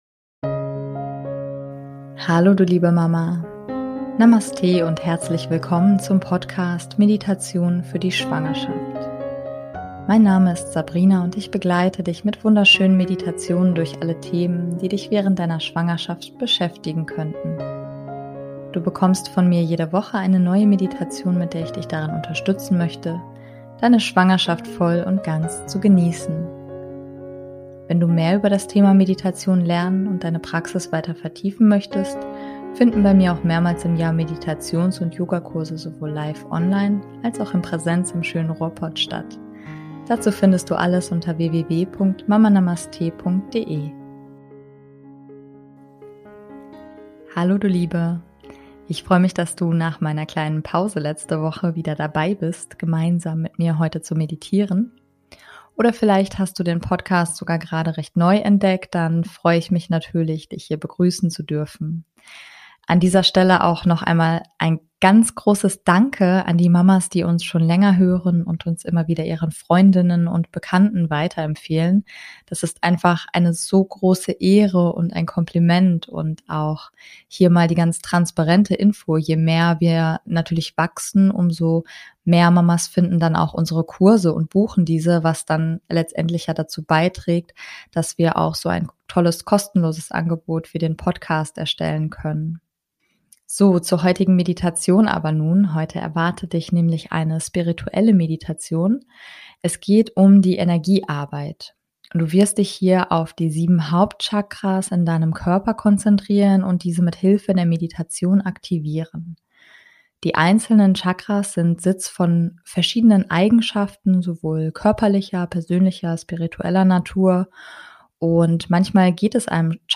Heute erwartet dich eine spirituelle Meditation, es geht nämlich um die Energiearbeit.